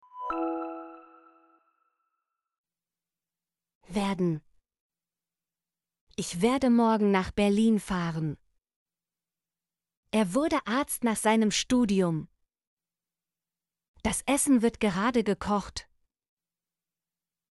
werden - Example Sentences & Pronunciation, German Frequency List